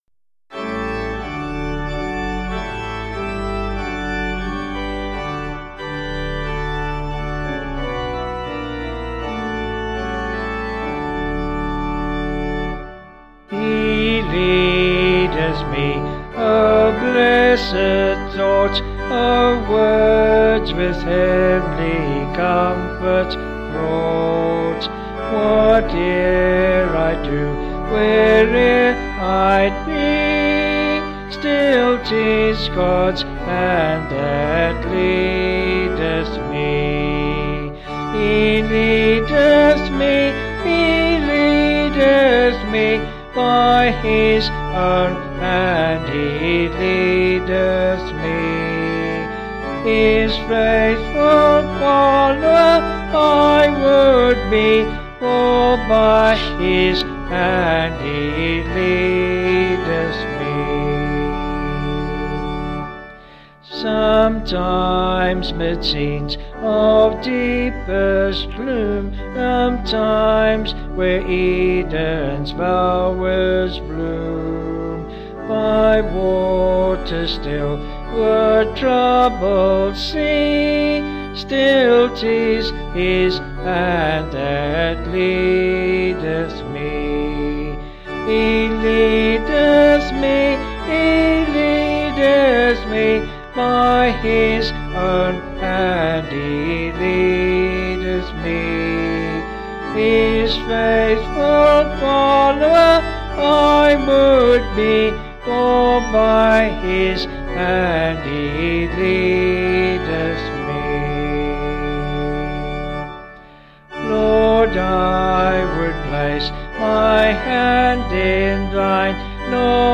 Vocals and Organ   264.8kb Sung Lyrics